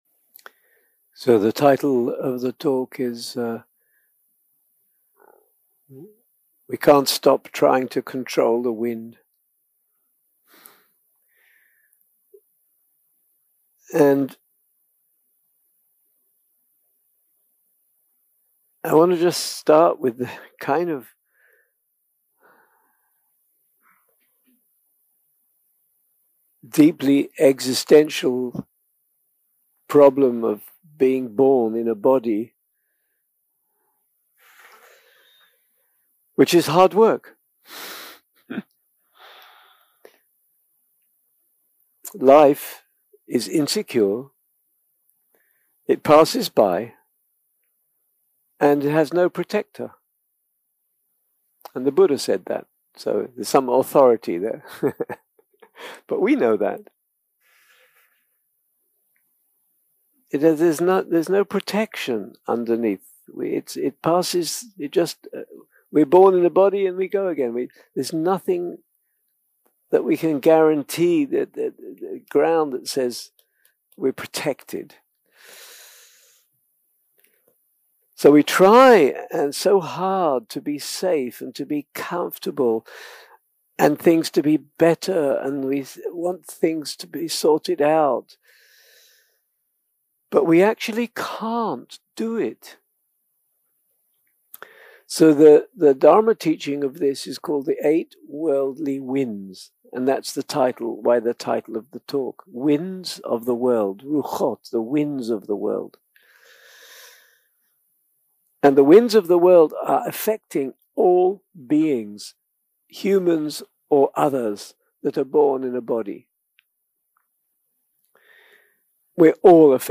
יום 4 – הקלטה 9 – ערב – שיחת דהארמה – We Cant Stop Trying To Control The Wind 0:00 0:00 סוג ההקלטה: סוג ההקלטה: שיחות דהרמה שפת ההקלטה: שפת ההקלטה: אנגלית